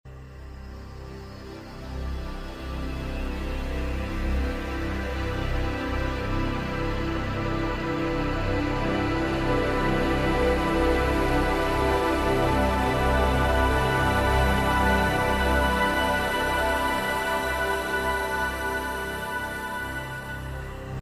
this one (sorta) loops too!